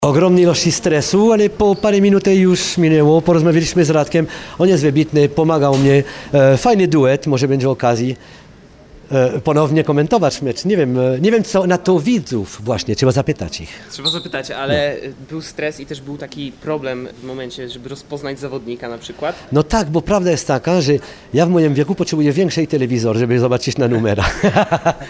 Mecz komentował niecodzienny duet – były piłkarz Radosław Majdan i kucharz Michael Moran. Po spotkaniu juror „MasterChefa” podzielił się z nami swoimi wrażeniami z nowej roli.